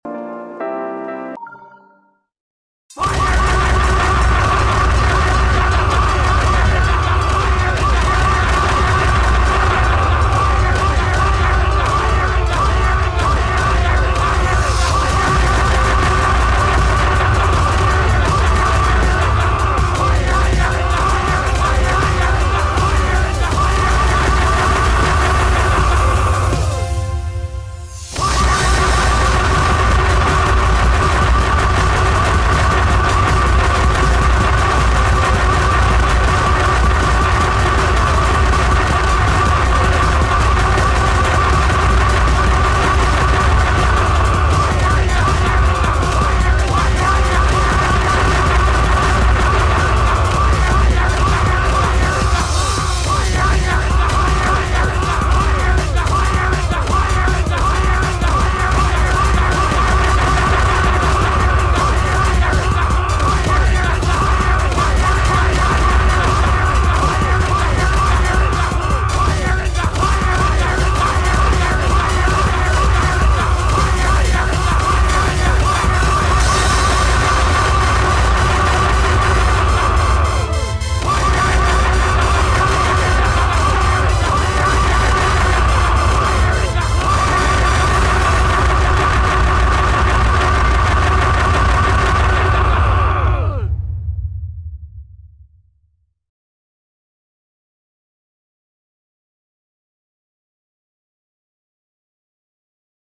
clicks sound (so loud)